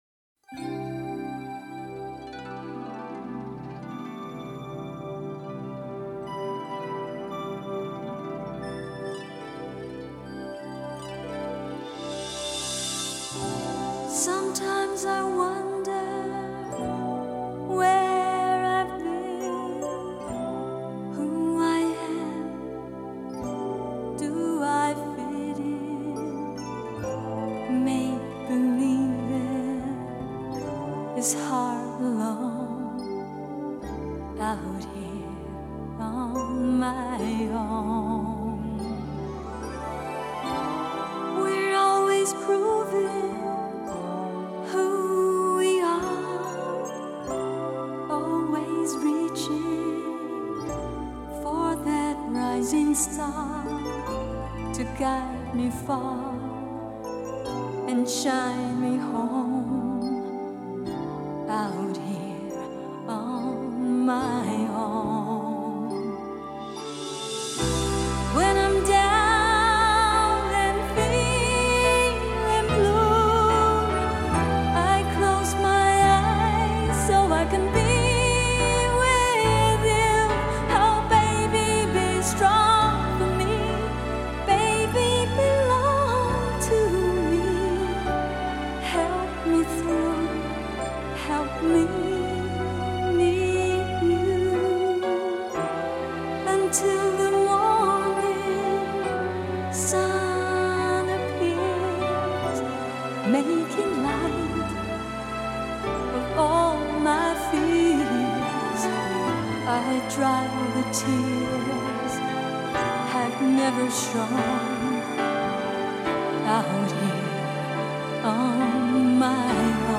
以清亮高音以及广阔的音域，诠释经典老歌，呈现出空灵而唯美的独特感受